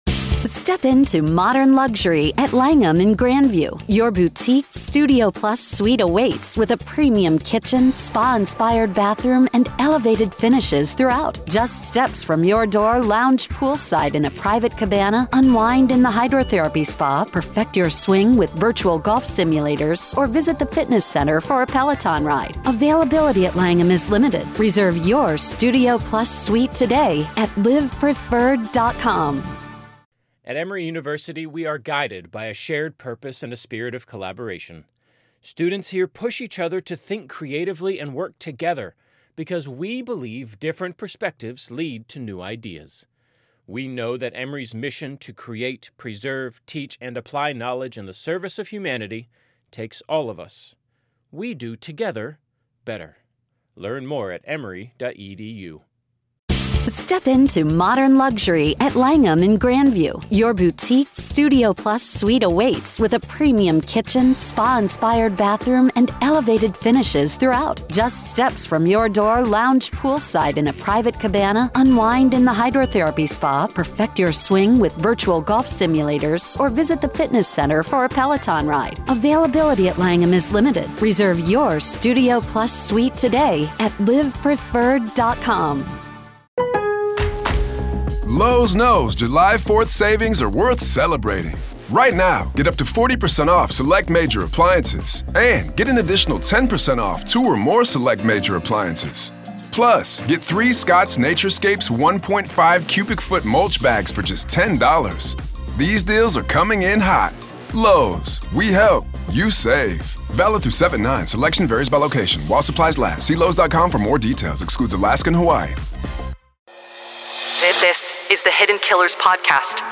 Dr. Katherine Ramsland Interview Behind The Mind Of BTK Part 3